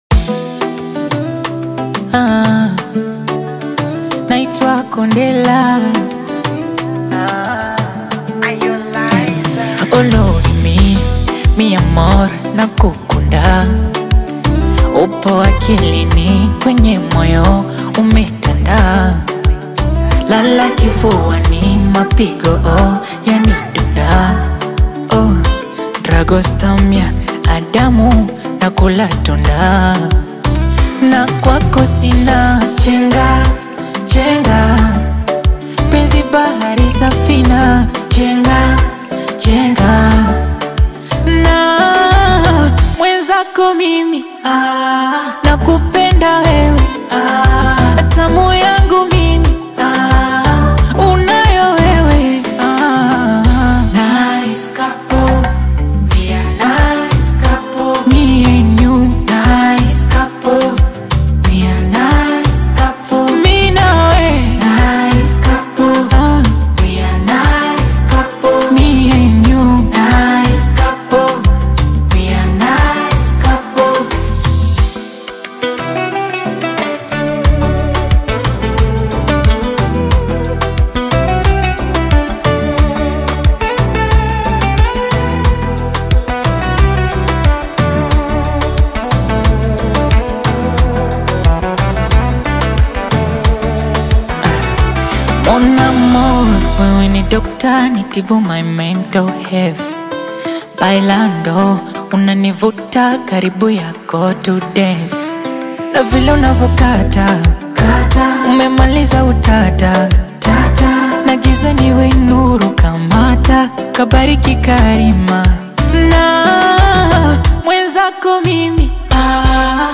Bongo Flava
is a smooth and emotionally rich love song